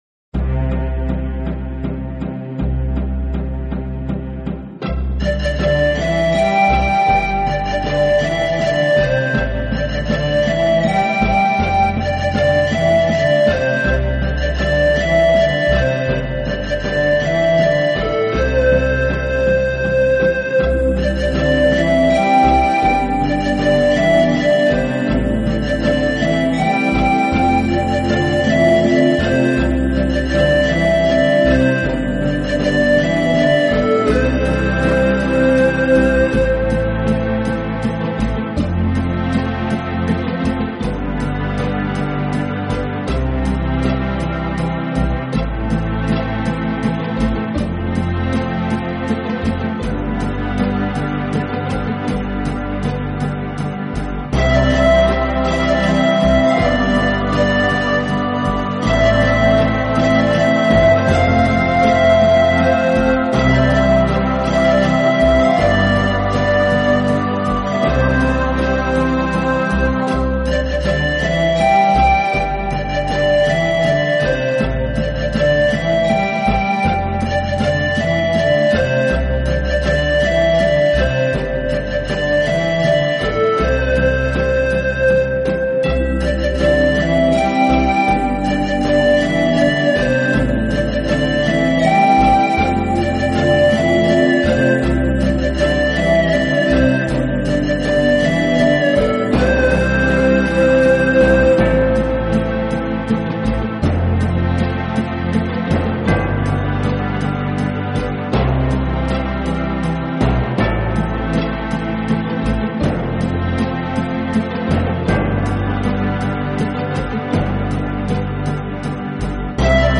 Style: Easy Listening
经典名曲的排萧重新演绎，排萧的演奏悠扬中
带有现代韵味，优质音色令人感觉出尘脱俗。